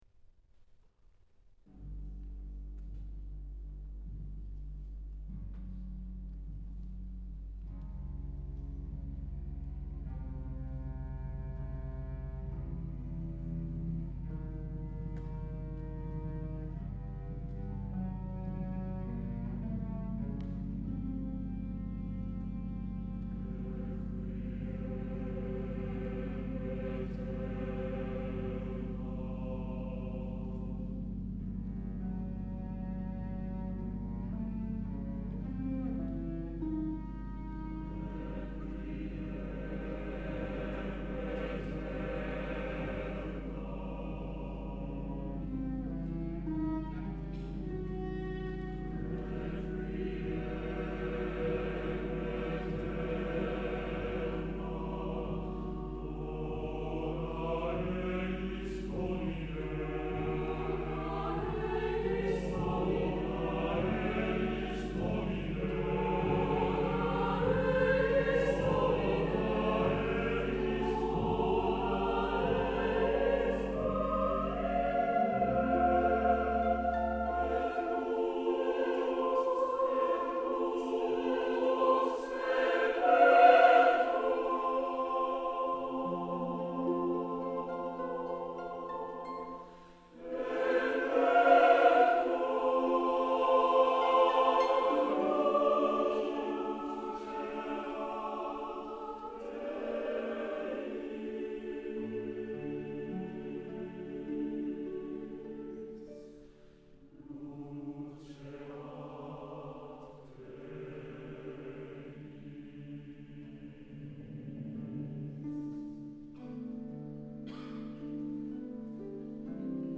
川口リリア音楽ホール